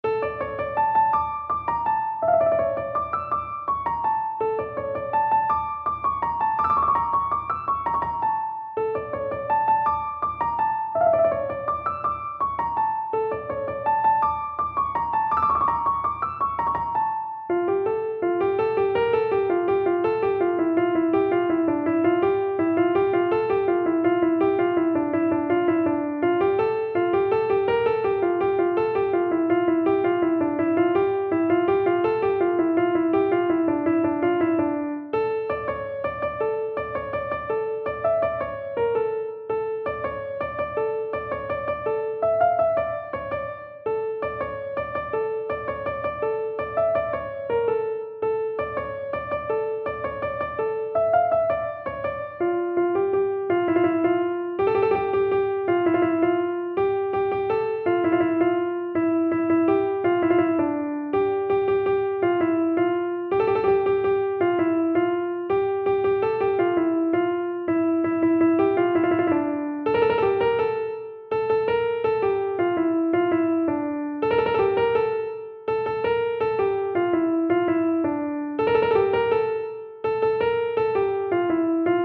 تنظیم شده برای کیبورد